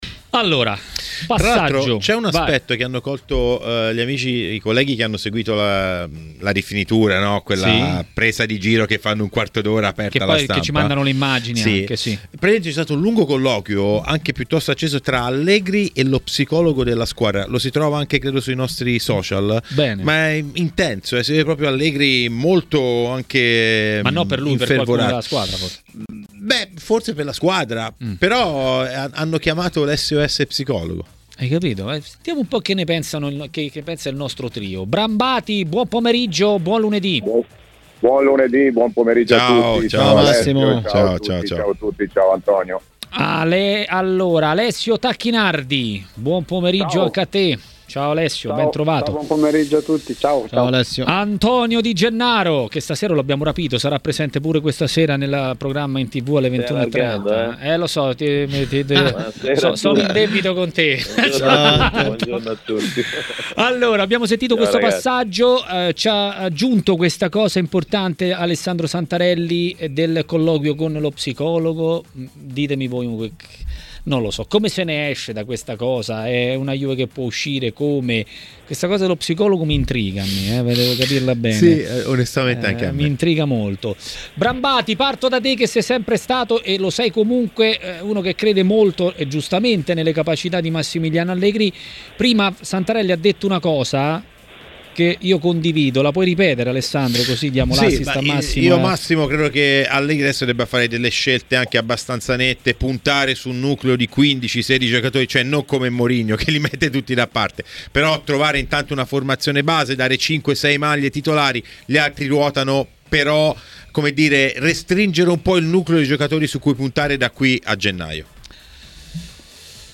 nel pomeriggio di TMW Radio, ha parlato di Juventus.